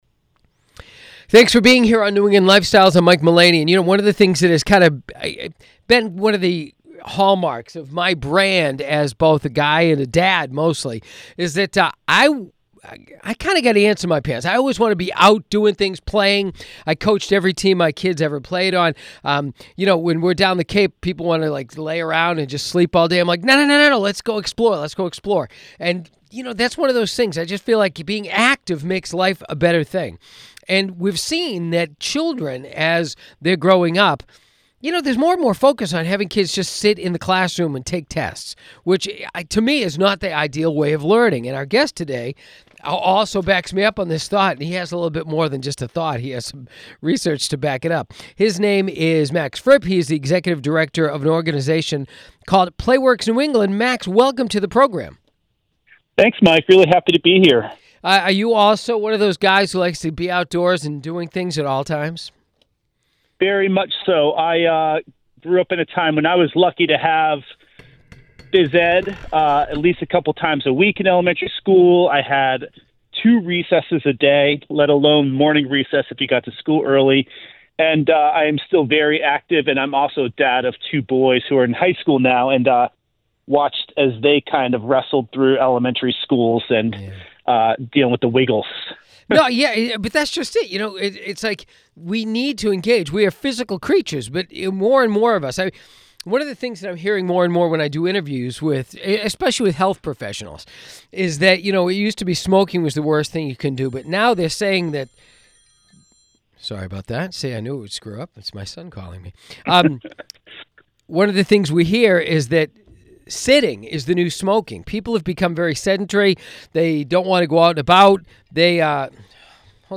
The interview aired across Big 103.3, Magic 106.7, Mix 104.1, and New England Sports Radio WEEI.